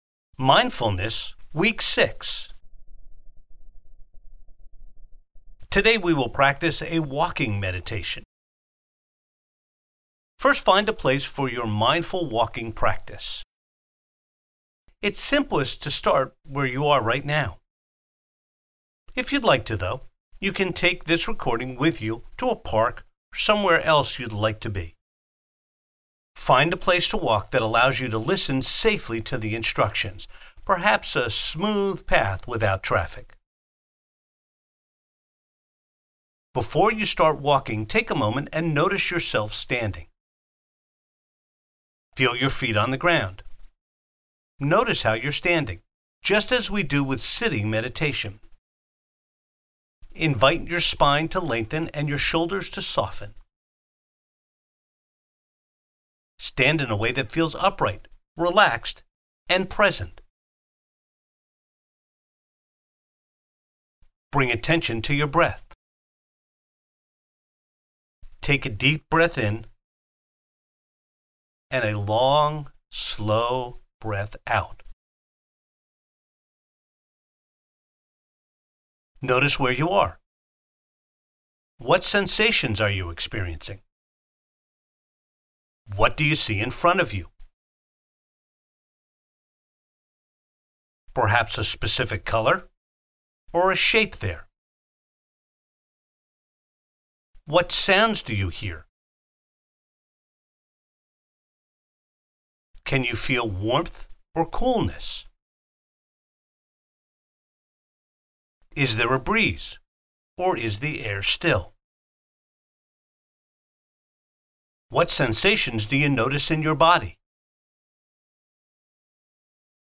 walking-meditation.mp3